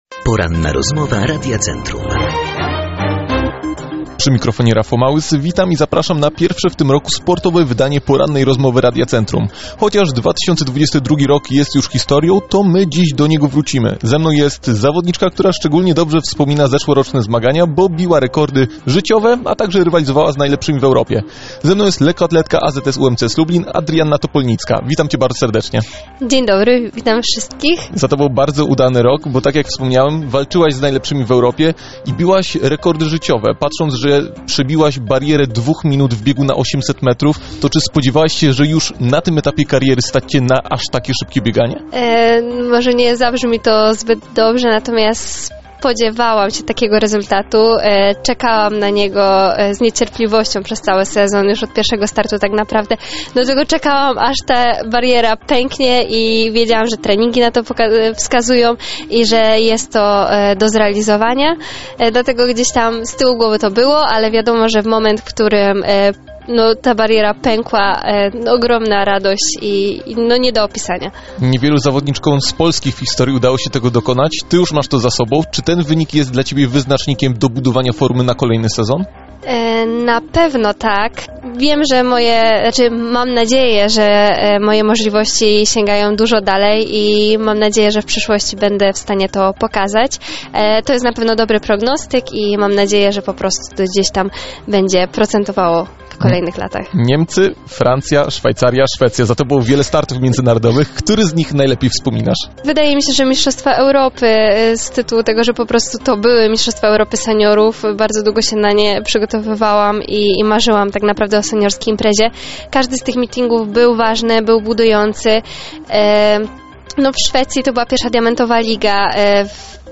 ROZMOWA
ROZMOWA.mp3